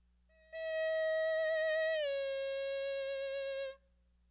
7) Simulating bone-transmission sound
I think it places somewhere between air and bone-conducted sounds.